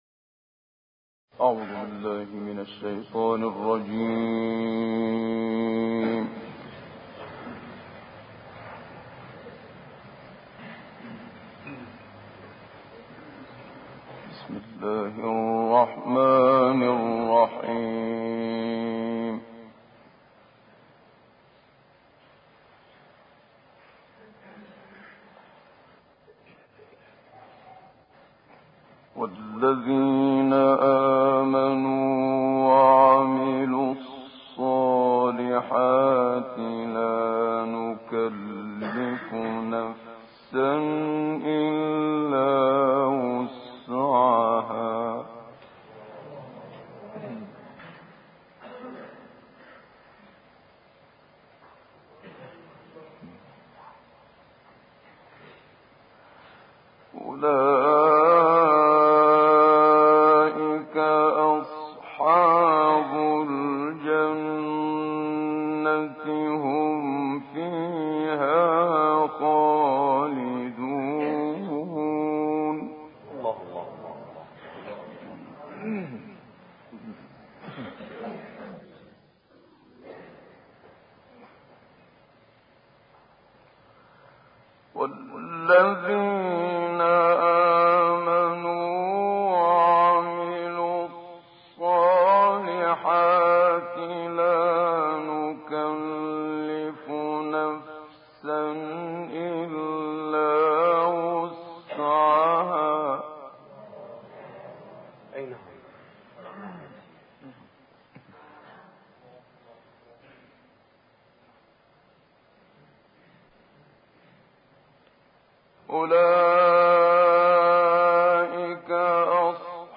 মুহাম্মাদ সাদিক মানশাভীর সুললিত কণ্ঠে সূরা আরাফ তিলাওয়াত
বসন্তের আগমনে আমরা মিশরের প্রসিদ্ধ ক্বারি মুহাম্মাদ সাদিক মানশাভীর সুললিত কণ্ঠে বসন্তের আলোকে পবিত্র কুরআনের আয়াতের তিলাওয়াত শুনবো।
লিবিয়ায় অনুষ্ঠিত এক কুরআন মাহফলিলে বিশ্ব বিখ্যাত ক্বারি মুহাম্মাদ সাদিক মানশাভী এই তিলাওয়াতটি পেশ করেন। উক্ত মাহফিলি তিনি তার সুললিত কণ্ঠে সূরা আরাফের ৪২ থেকে ৭৪ নম্বর আয়াত পর্যন্ত তিলাওয়াত করেন।